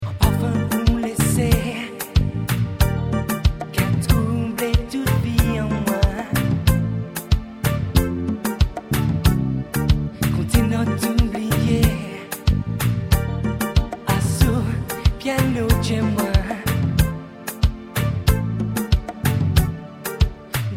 Le style : Zouk Love Antillais aux influences Reggae, Ragga